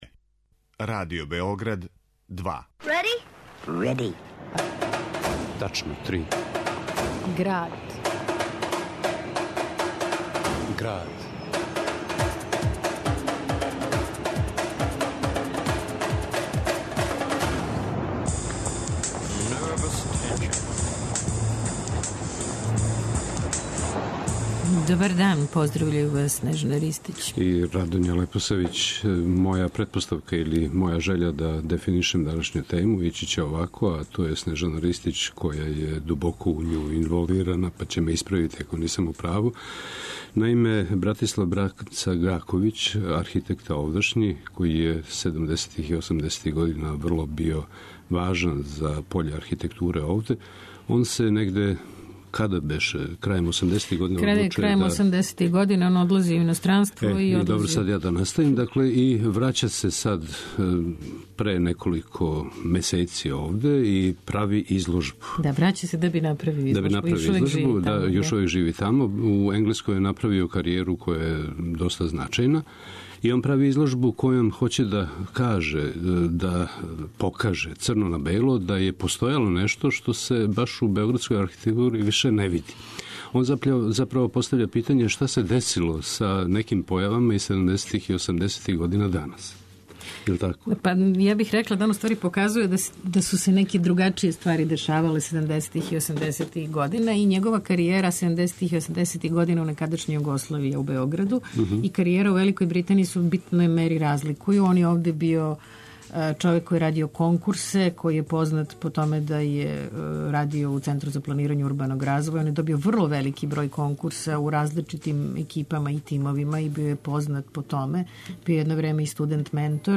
У Граду, снимци и коментари.